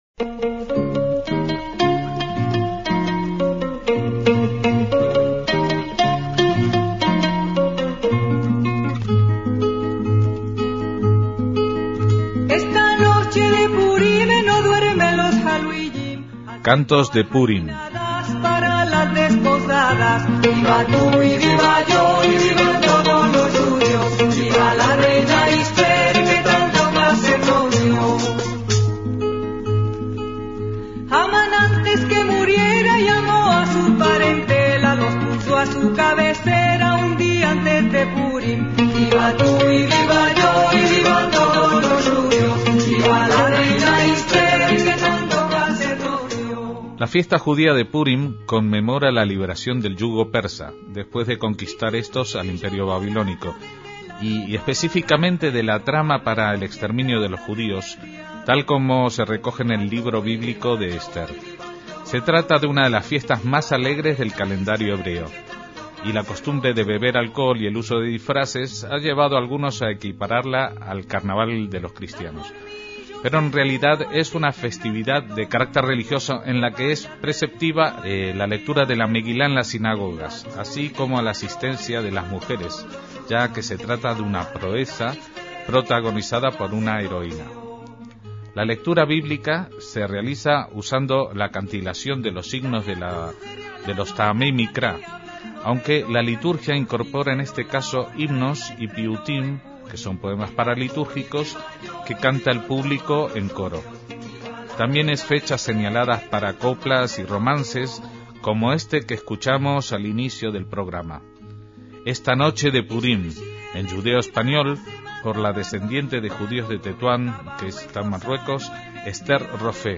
Cantos de Purím